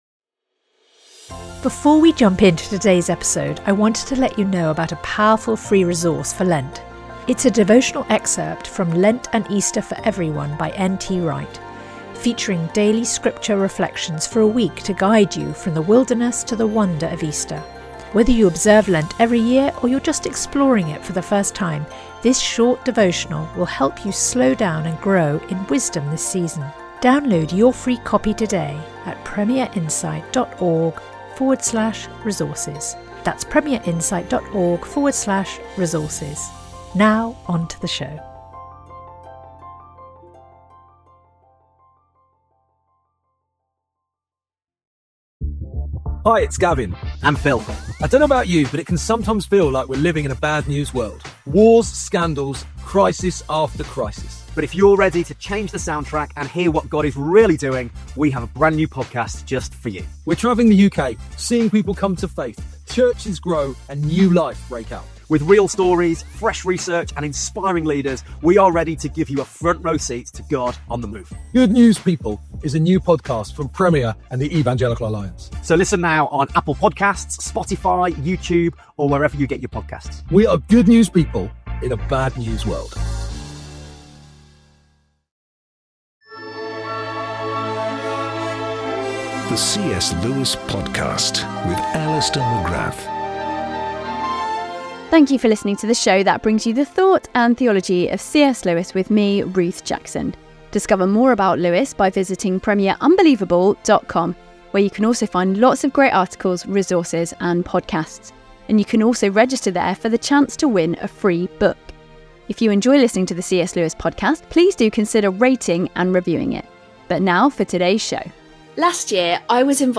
In this special edition of the CS Lewis Podcast, we’re sharing a conversation first recorded for the Lesser Known Lewis podcast, kindly made available for our listeners.